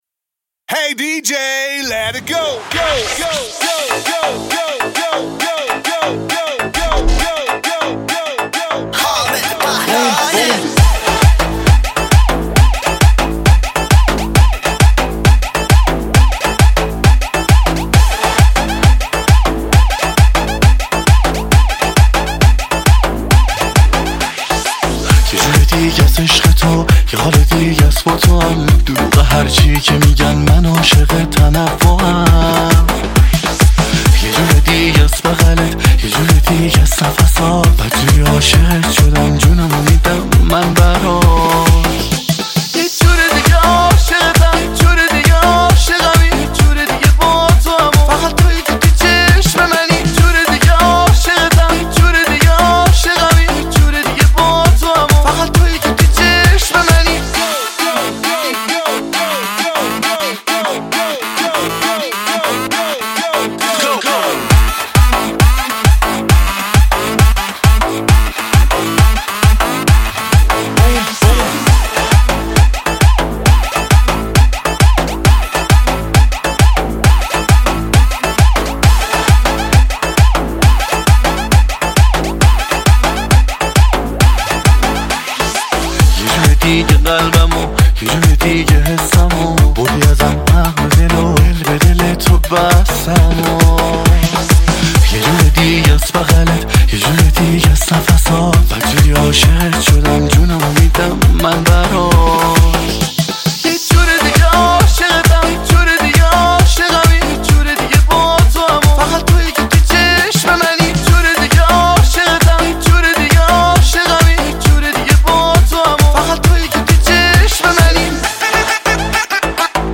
آهنگ شاد
تک اهنگ ایرانی